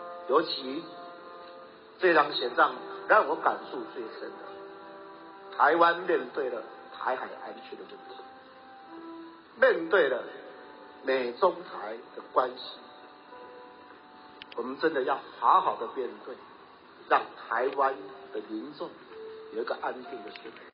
侯友宜发表败选感言